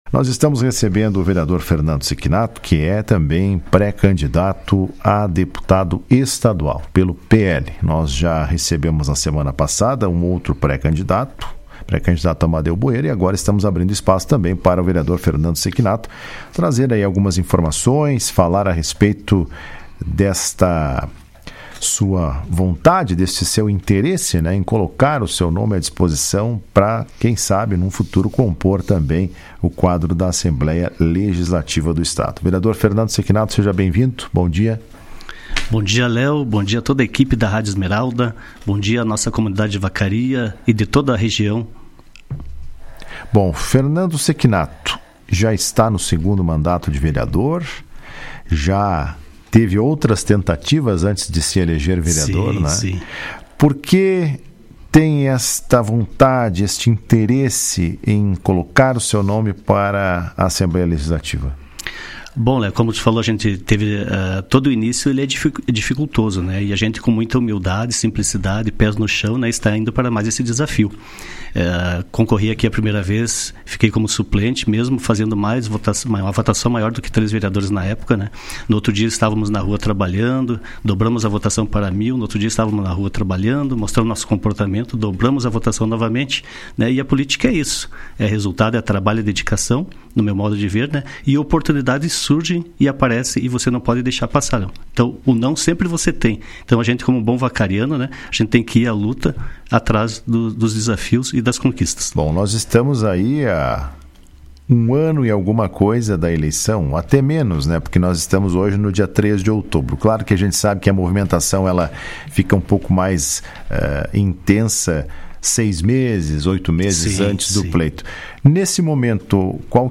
Em entrevista à Rádio Esmeralda, ele afirmou que está em pré-campanha e tem feito contato com municípios e lideranças da região para que possa alcançar seu objetivo.